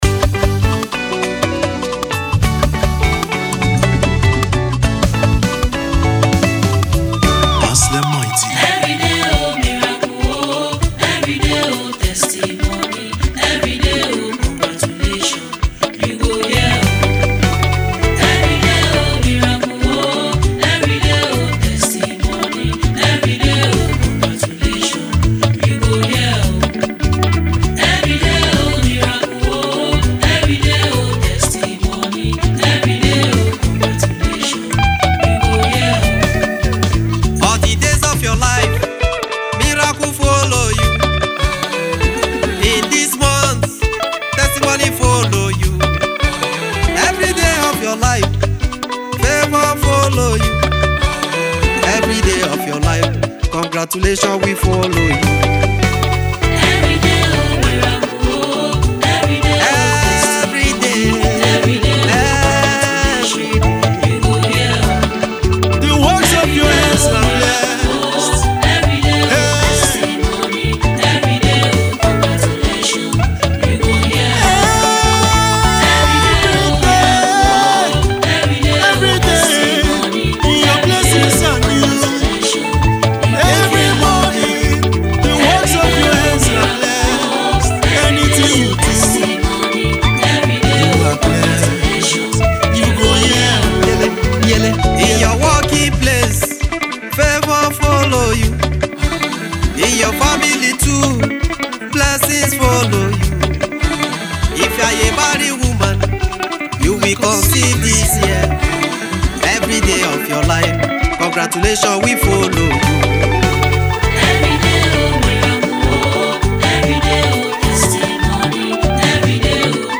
Nigerian gospel music artist